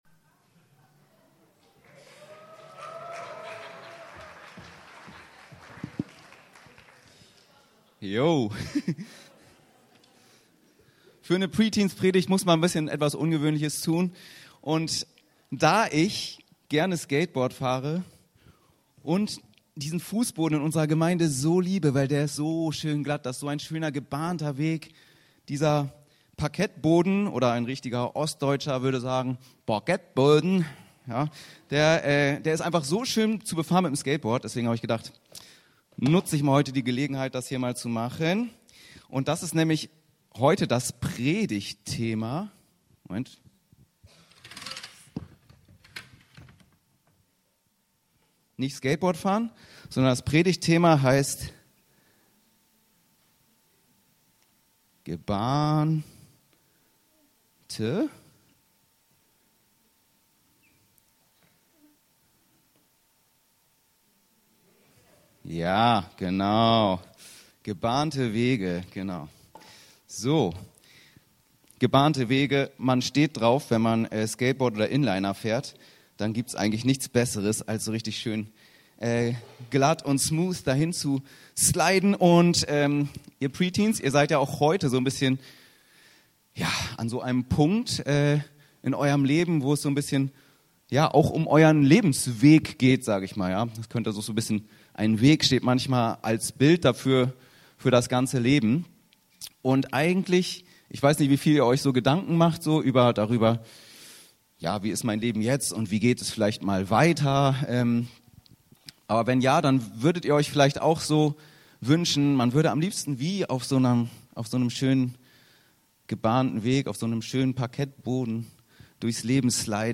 Gebahnte Wege im Herzen ~ Anskar-Kirche Hamburg- Predigten Podcast
Eines der vielen eindrücklichen Bilder für ein gelingendes Leben finden wir im 84. Psalm: "Gebahnte Wege im Herzen". Zum Anlass des Preteens Abschlusses wollen wir schauen, wie die gebahnten Wege aussehen und wo sie hinführen.